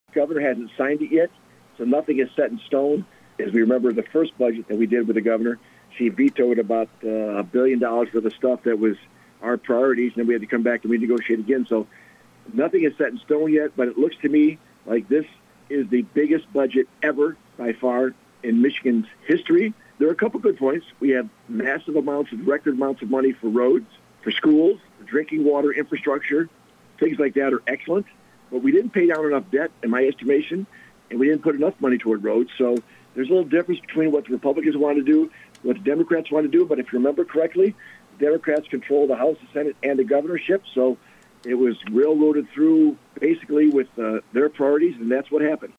Adrian, MI – The Michigan House and Senate have approved the State budget for the next fiscal year, and the Senator for the majority of Lenawee County, Joe Bellino, gave his reaction on a recent 7:40am break.